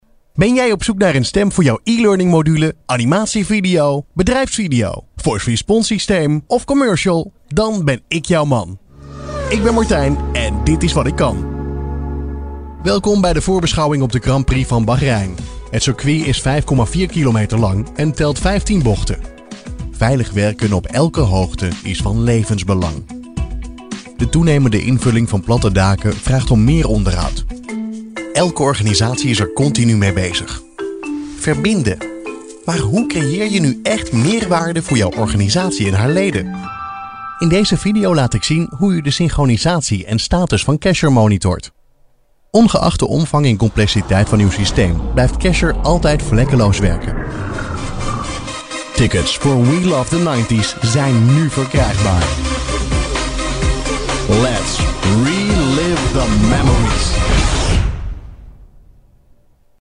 男荷02 荷兰语男声 广告MG大气沉稳品质 大气浑厚磁性|沉稳|娓娓道来|科技感|积极向上|时尚活力|素人